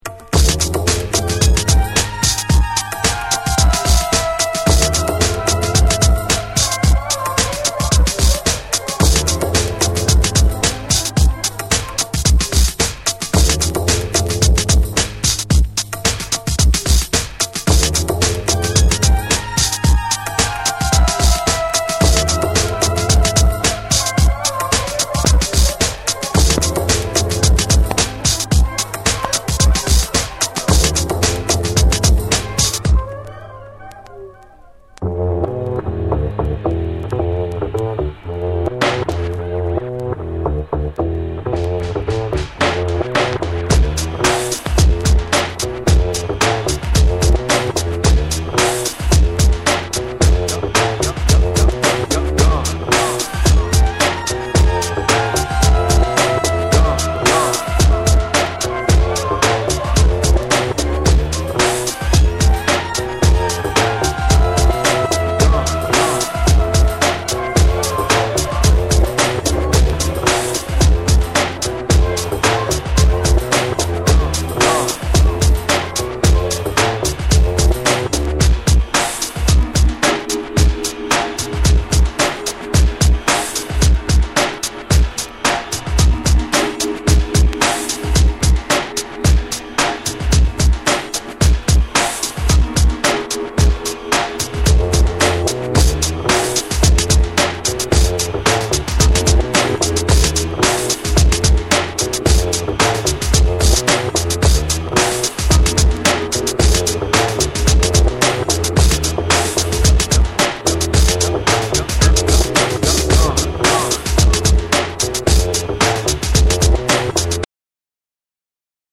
BREAKBEATS / ORGANIC GROOVE